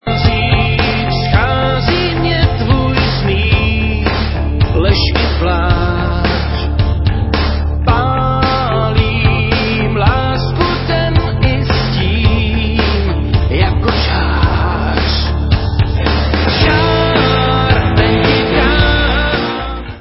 vocals, guitars
drums, vocals
keyboards
bass